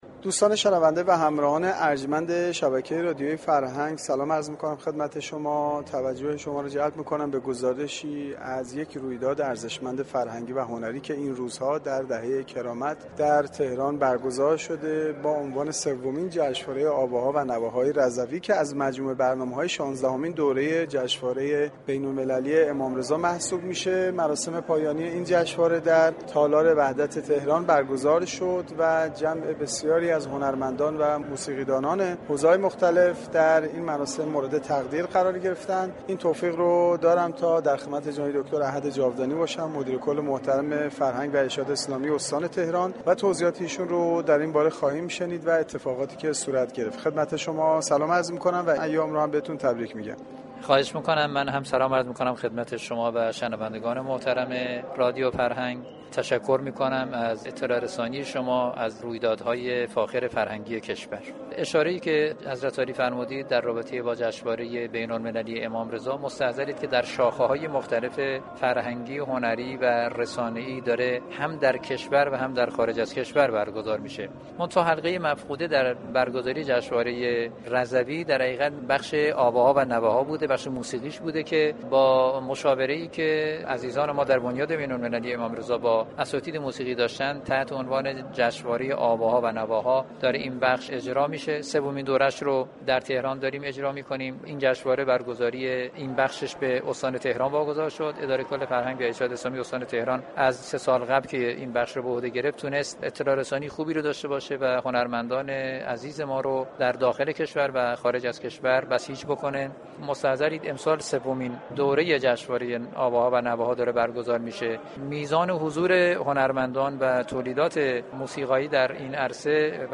دكتر احد جاودانی رییس سومین جشنواره آواها و نواهای رضوی و مدیر كل فرهنگ و ارشاد استان تهران در گفتگوی اختصاصی